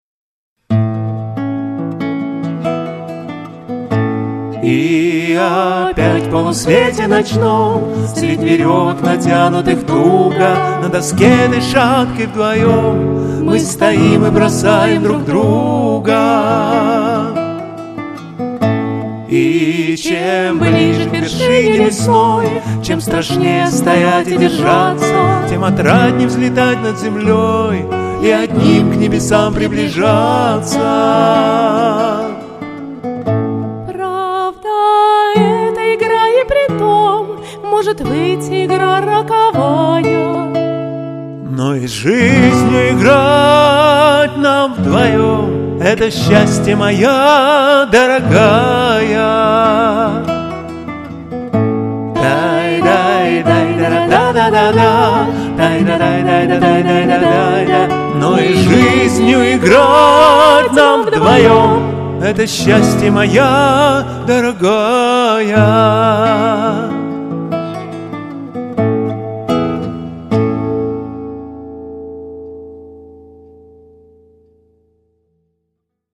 поющий в жанре лирической песни